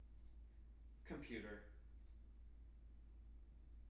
wake-word
tng-computer-398.wav